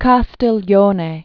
(kästĭl-yōnā, -stē-lyōnĕ), Count Baldassare 1478-1529.